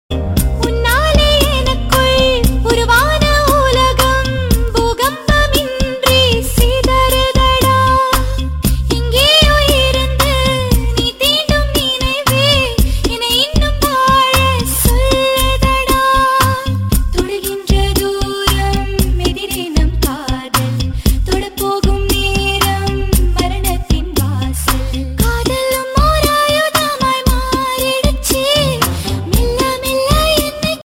best flute ringtone download